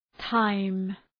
Προφορά
{taım}